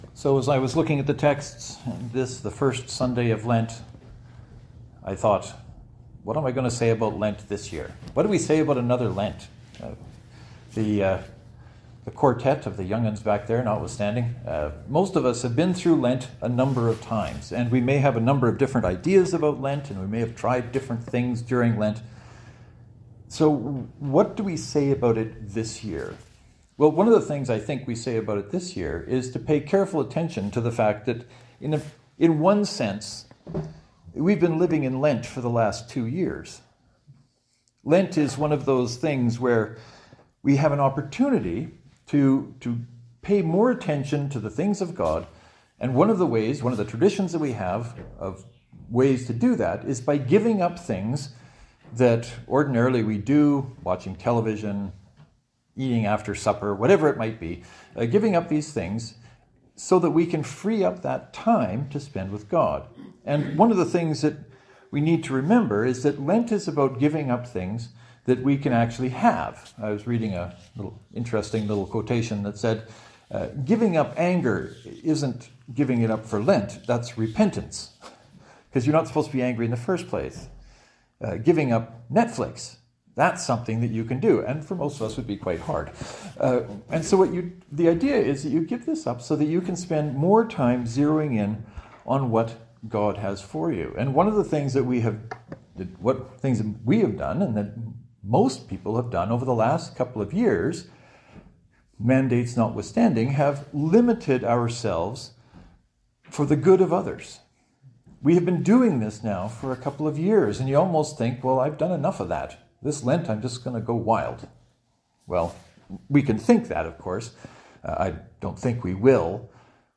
This is a new way for me to look at it and if you give either or both of the sermons a listen, I am curious if this is new to you too?